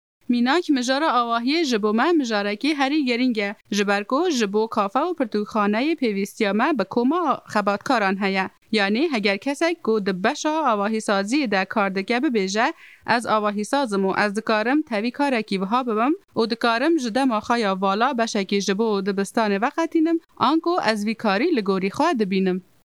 Female
Adult
Educational